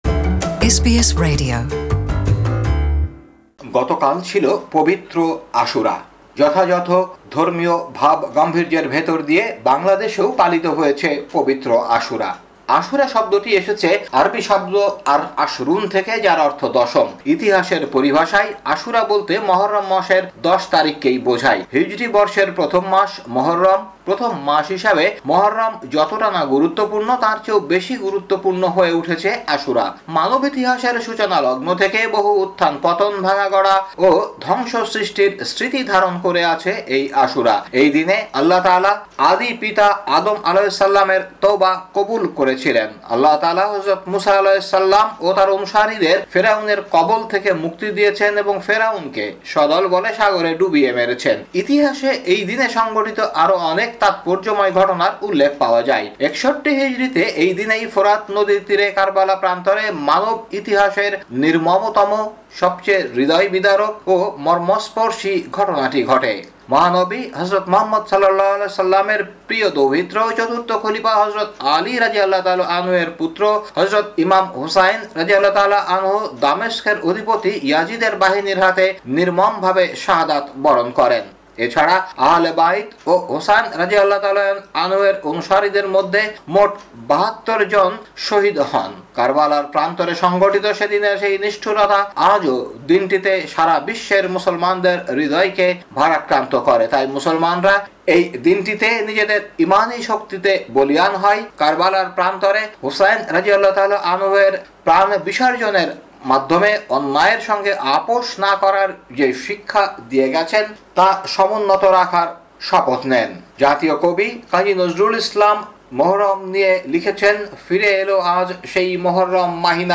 বাংলায় প্রতিবেদনটি শুনতে উপরের অডিও প্লেয়ারটিতে ক্লিক করুন।